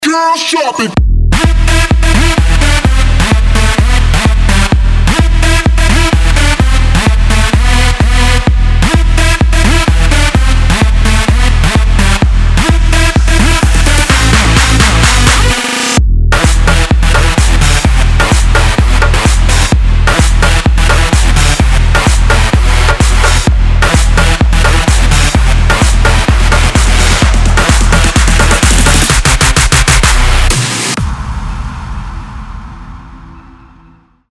• Качество: 320, Stereo
электронные